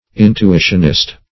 Search Result for " intuitionist" : Wordnet 3.0 ADJECTIVE (1) 1. of or relating to intuitionism ; The Collaborative International Dictionary of English v.0.48: Intuitionist \In`tu*i"tion*ist\, n. Same as Intuitionalist .